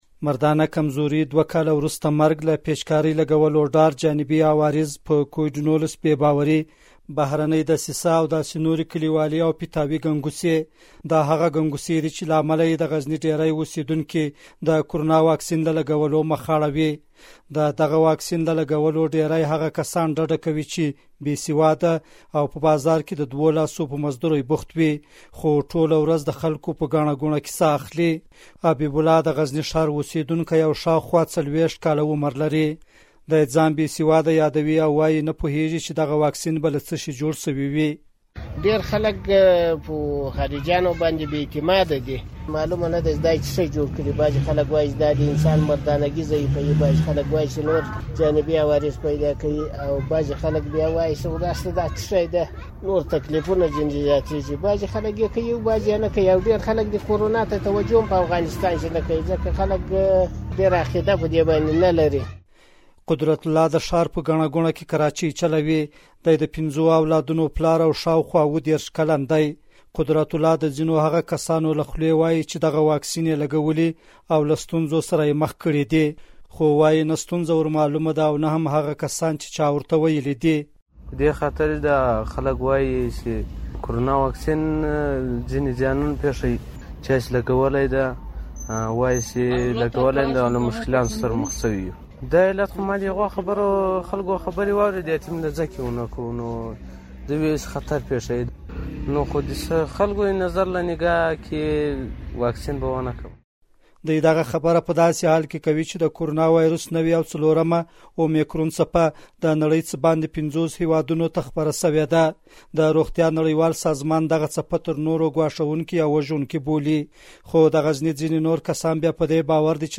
د غزني راپور